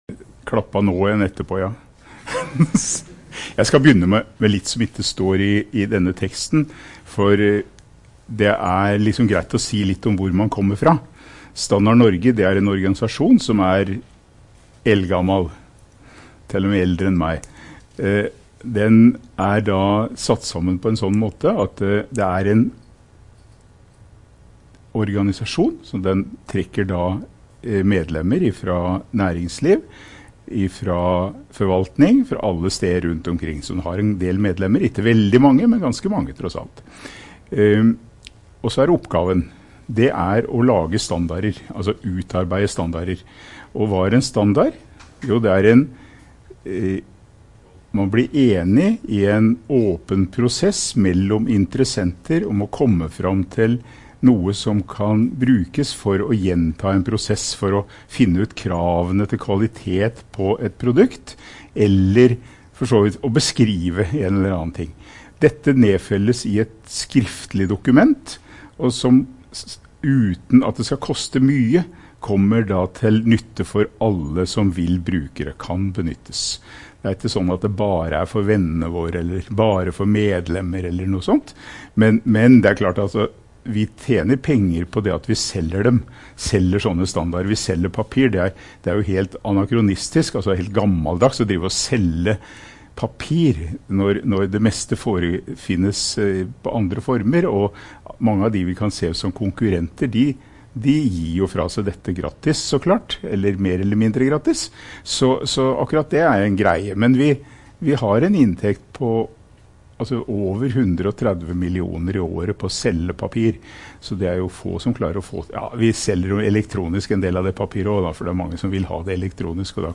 Risk management guest lecture